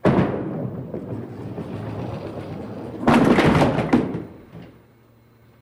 Звуки боулинга